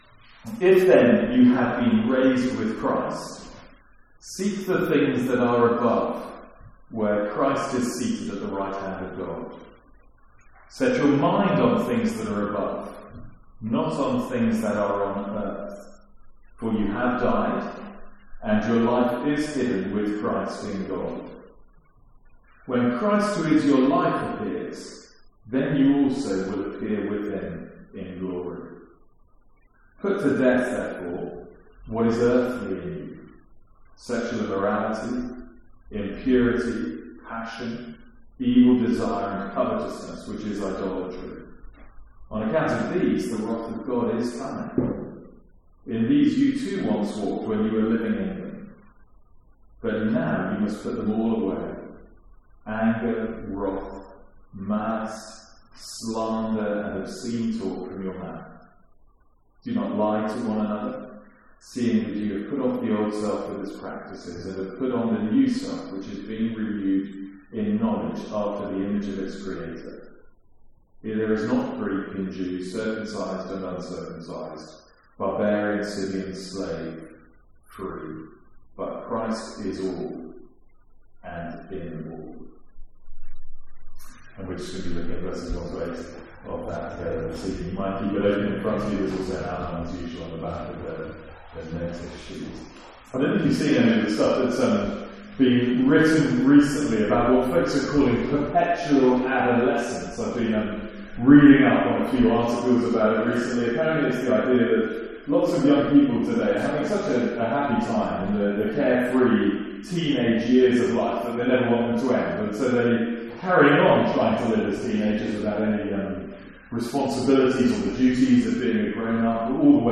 Apologies for the poor audio quality.